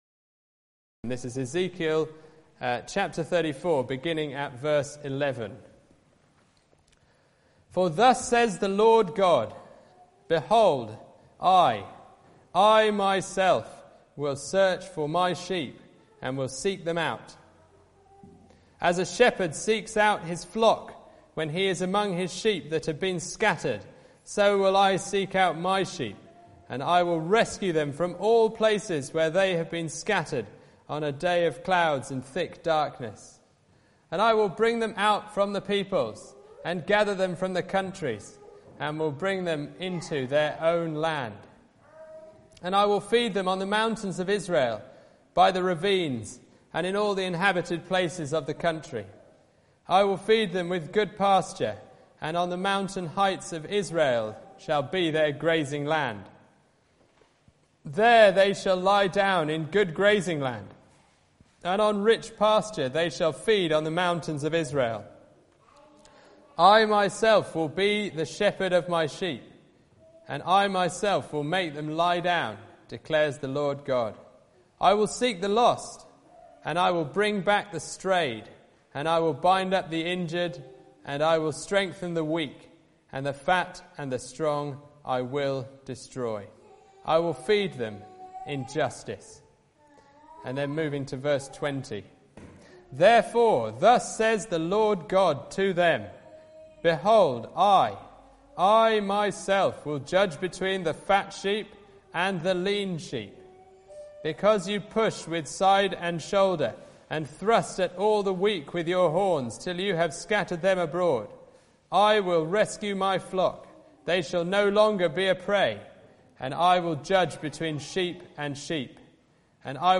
John 10:1-18 Service Type: Sunday Morning Bible Text